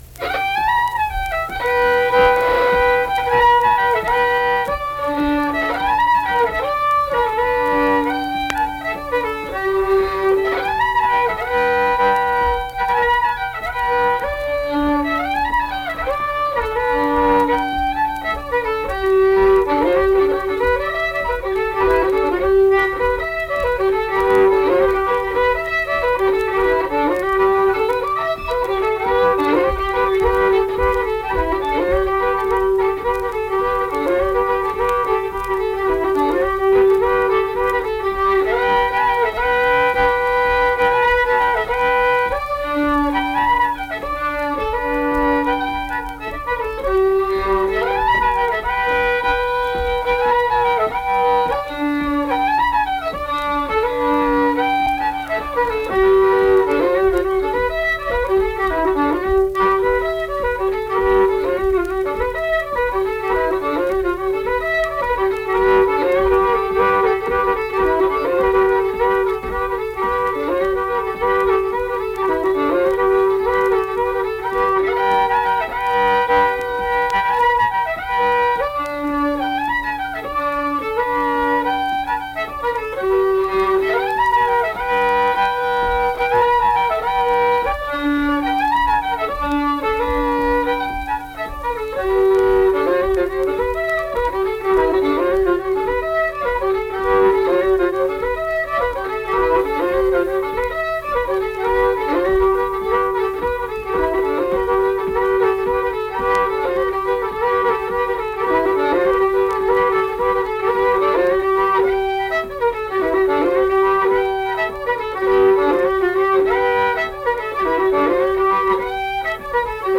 Accompanied guitar and unaccompanied fiddle music performance
Instrumental Music
Fiddle